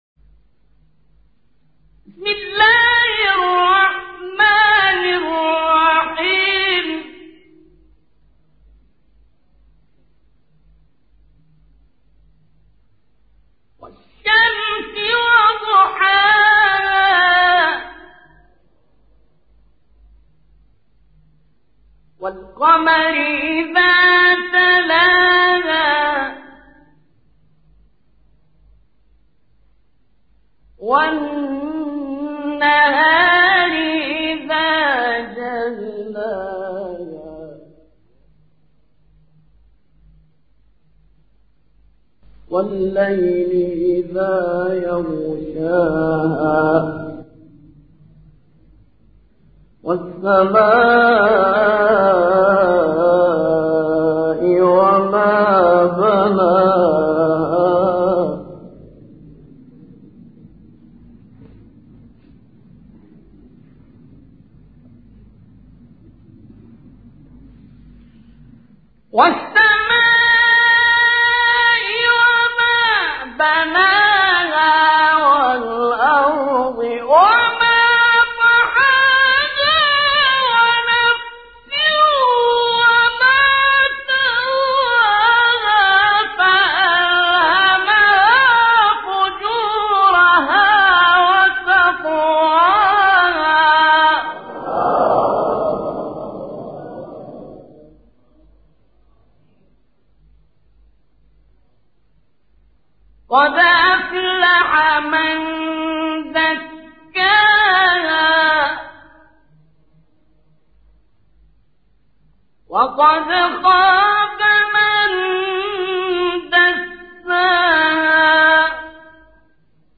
تلاوت سوره شمس